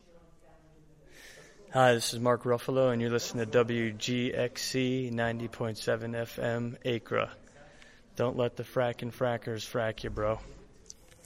Mark Ruffalo WGXC station identification.
Official station identification for WGXC recorded by actor Mark Ruffalo.